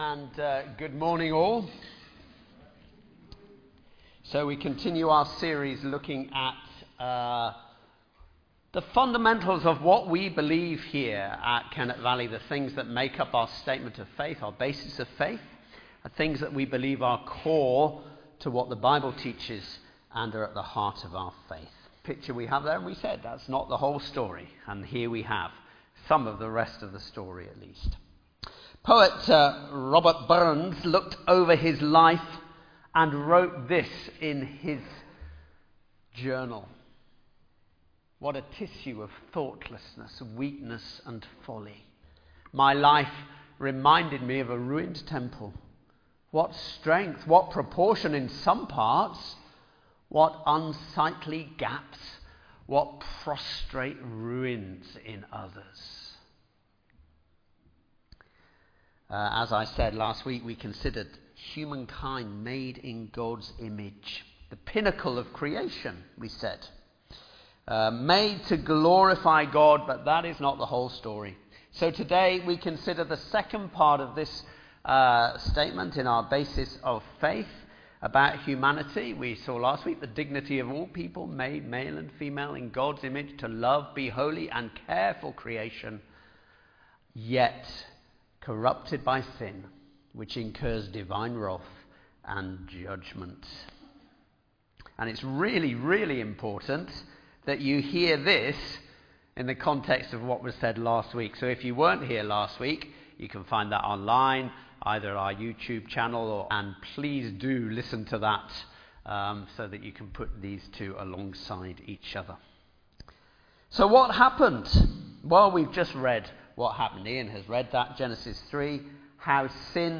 Sermon-2nd-November-2025.mp3